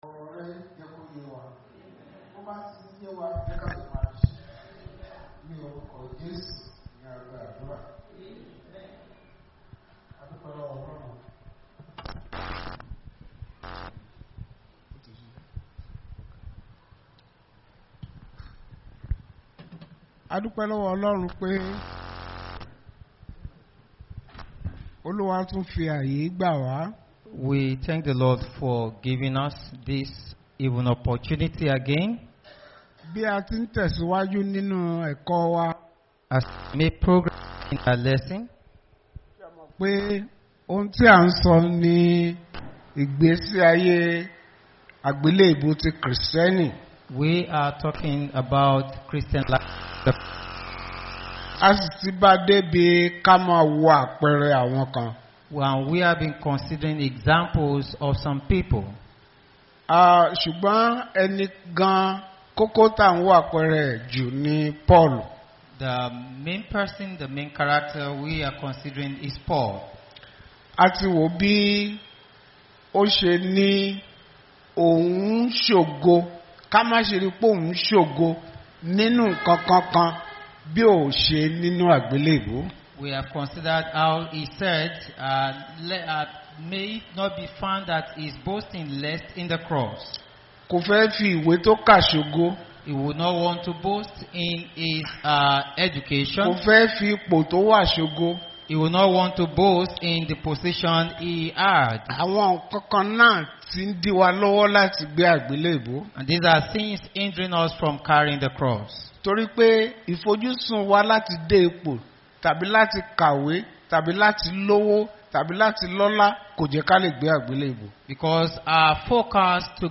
Bible Class Passage: Philippians 3:3-10, Matthew 16:21-26